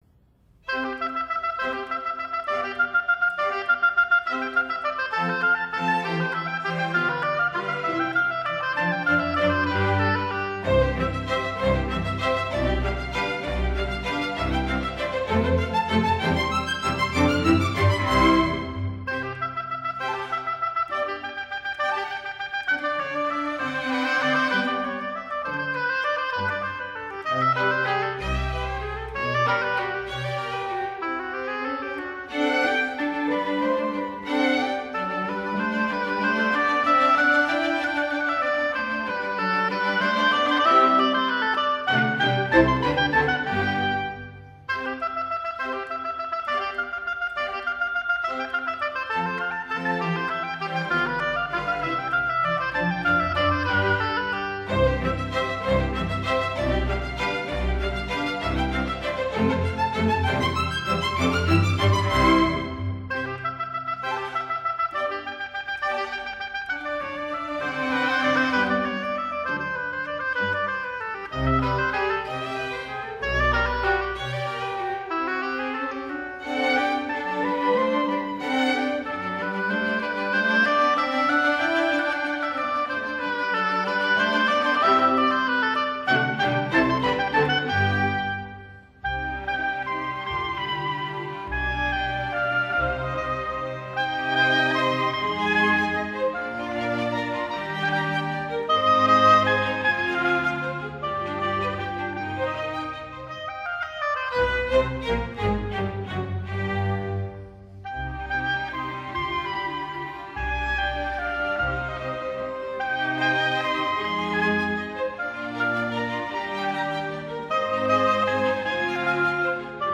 Concerto pour hautbois - 4e mvt : Allegro